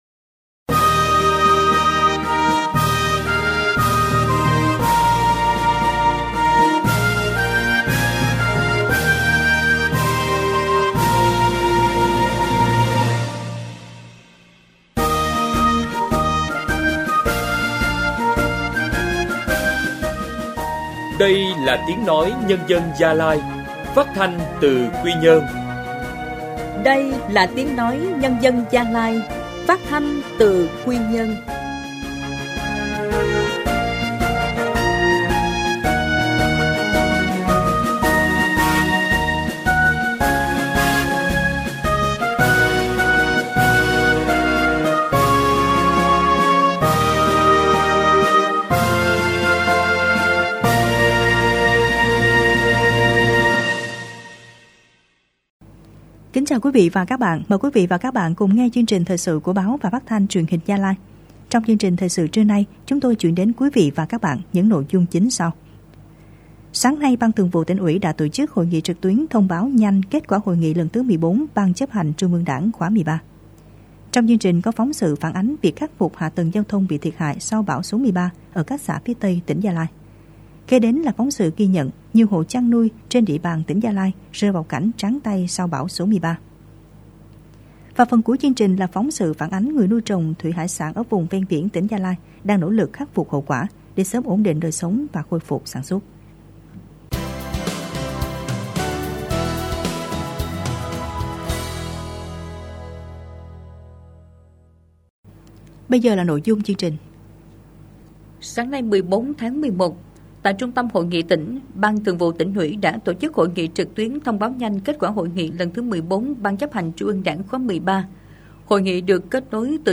Thời sự phát thanh trưa